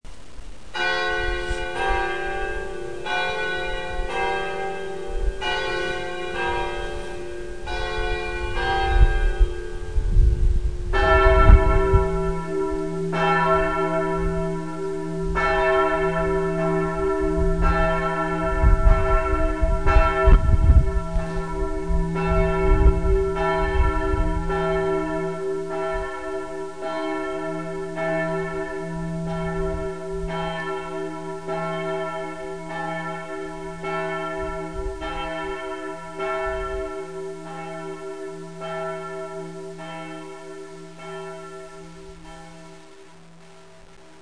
church bell sounds from up on the hill, close your eyes and you're almost back in the National Opera House in London, waiting for the evening to begin.
barga duomo bells small.mp3